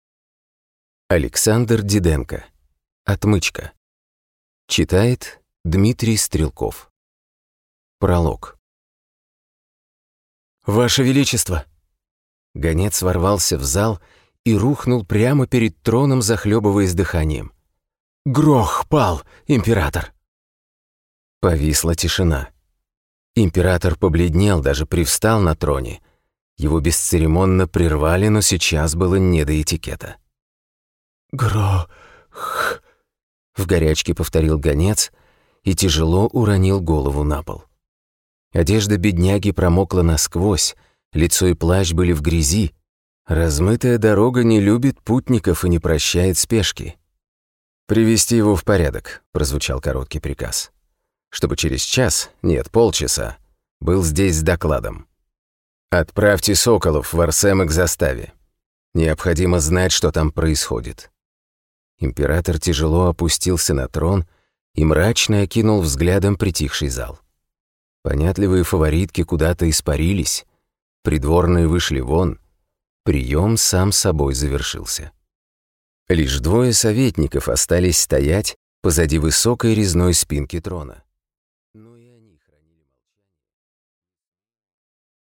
Аудиокнига Отмычка | Библиотека аудиокниг